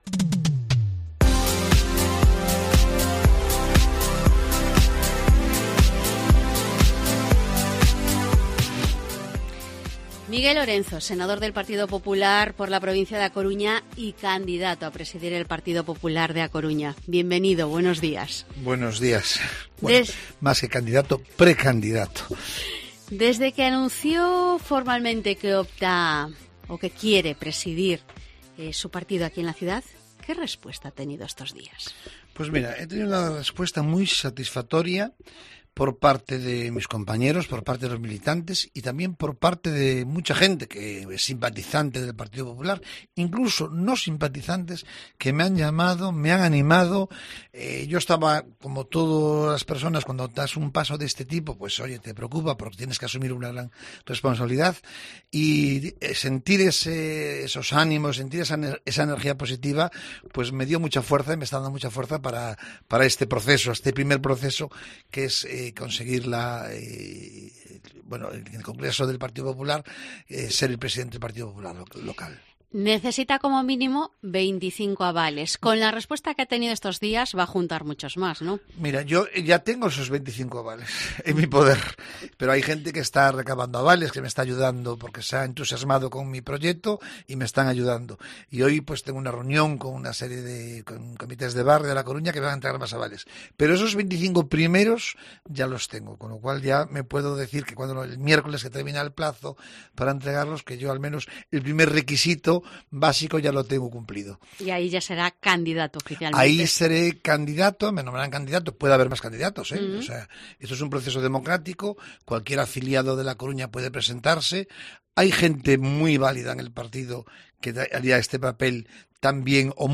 El popular ya tiene garantizados los 25 avales para poder optar a ser presidente del partido en la ciudad, según ha confirmado en una entrevista en COPE Coruña.